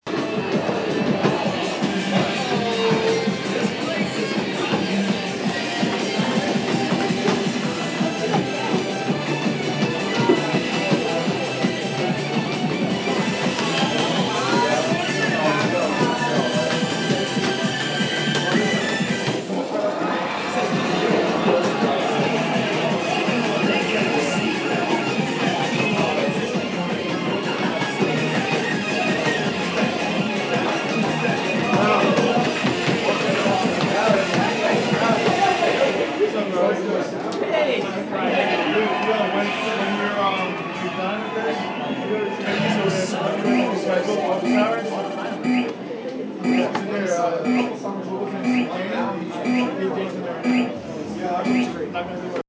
This recording was taken at Kate and Willies. Students are ordering food, talking with their friends, and playing DDR.